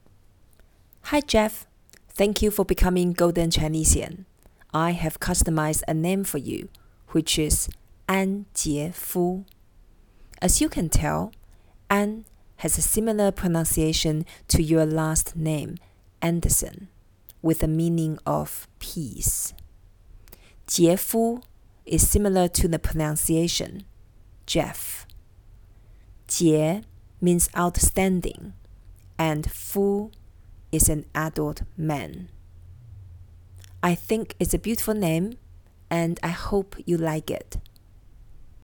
• Audio File with pronunciation